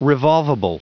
Prononciation du mot revolvable en anglais (fichier audio)
Prononciation du mot : revolvable